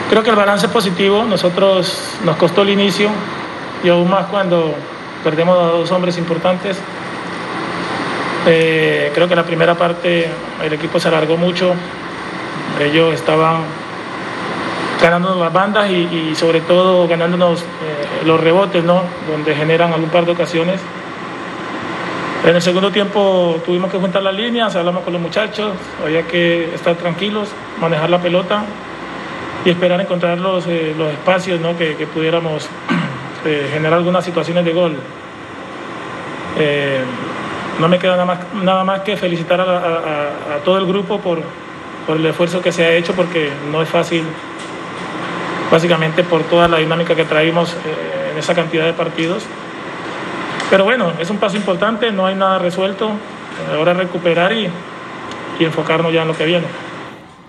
(Luis Amaranto Perea, DT del Junior)